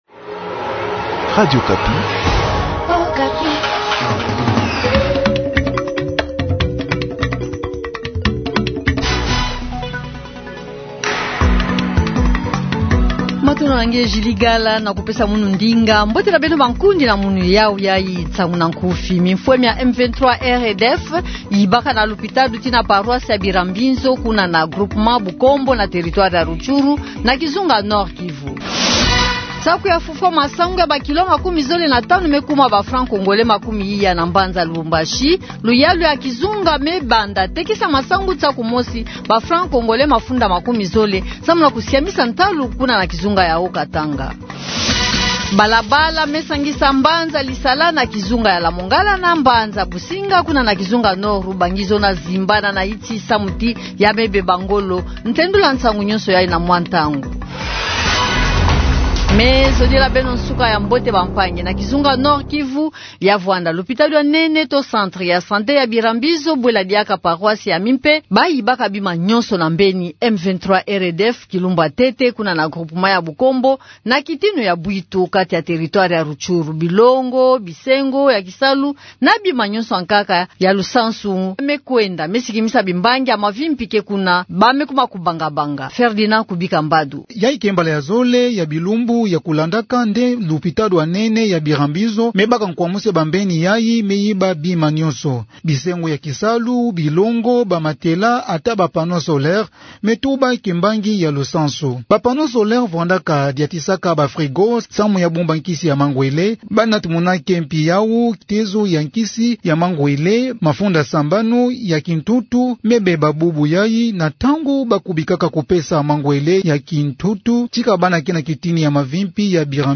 Journal du matin